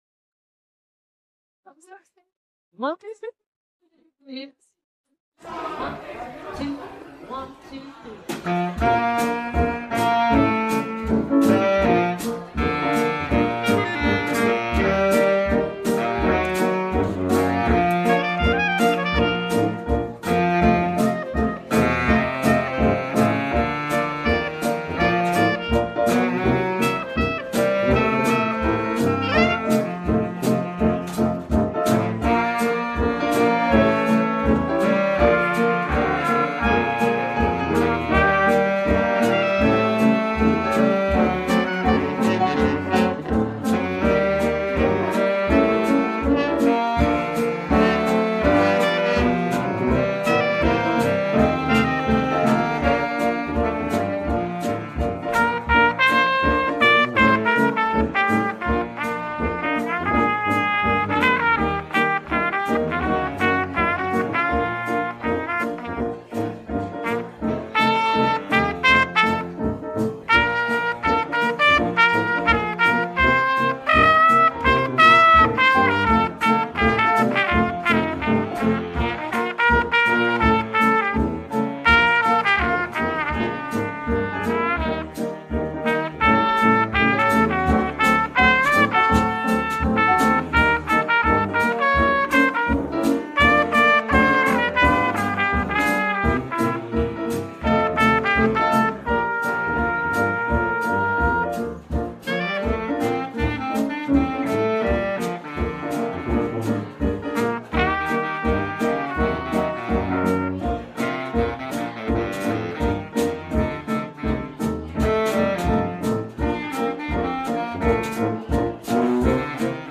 The Curse of an Aching Heart Music by Al Piantadosi, Lyrics by Henry Fink 1913 played by the Summer Street Stompers
Lechuga Fresca Latin Band and Summer Street Stompers Dixieland Band